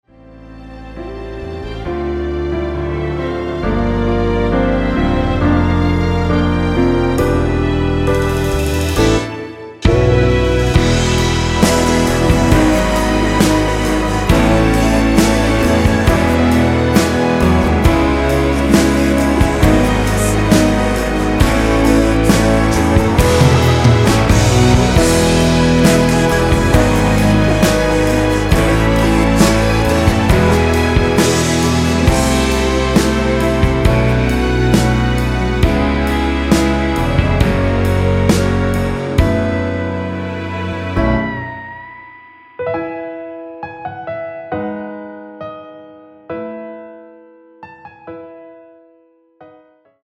이곡의 코러스는 미리듣기에 나오는 부분밖에 없으니 참고 하시면 되겠습니다.
원키 코러스 포함된 MR입니다.(미리듣기 확인)
앞부분30초, 뒷부분30초씩 편집해서 올려 드리고 있습니다.